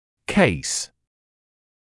[keɪs][кэйс]случай; клинический случай